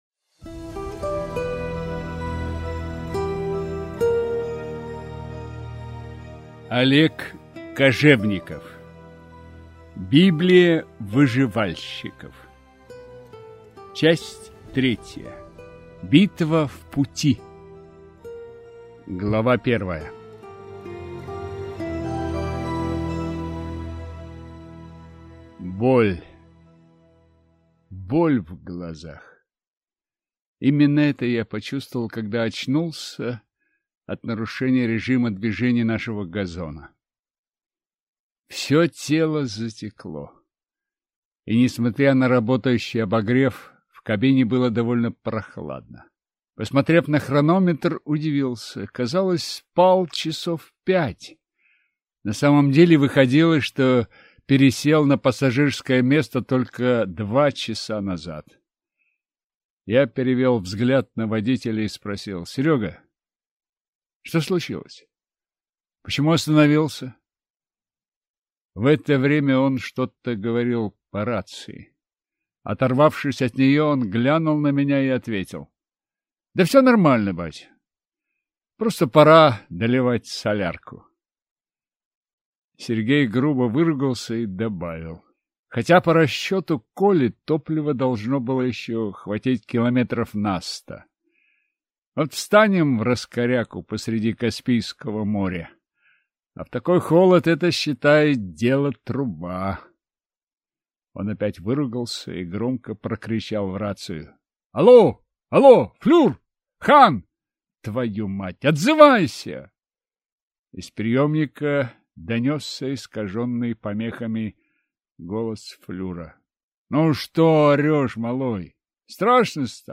Аудиокнига Библия выживальщиков. Битва в пути | Библиотека аудиокниг